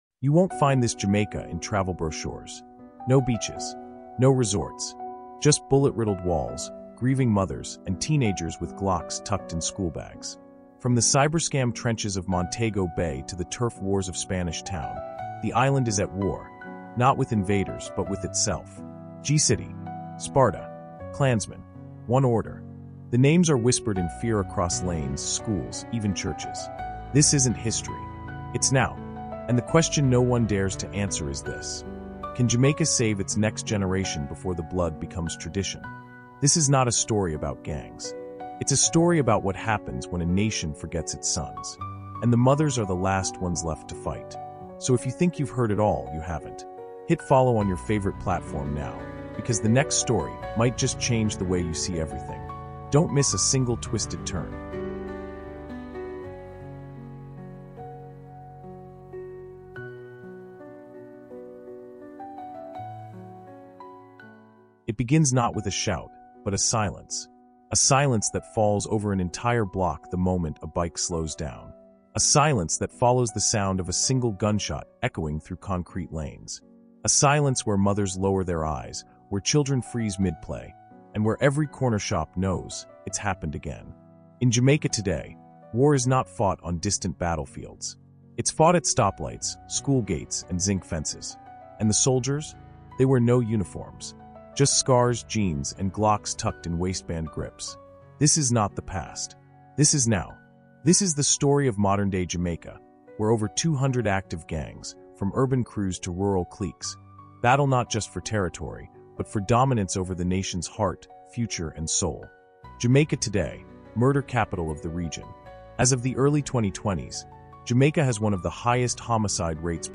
Jamaica’s Modern Gangs: The Hidden War Shaping the Island is a raw, deeply researched Caribbean history audiobook documentary exposing the violent, evolving gang landscape shaping modern Jamaica. This immersive 10-chapter audio series goes far beyond news headlines to deliver gripping true history stories about turf wars, digital crime, and cultural destruction.
Hear the real voices of survivors, insiders, and community leaders confronting: G-City vs Sparta: The gang war tearing apart Jamaica’s tourist hub The fall of the “Don” system and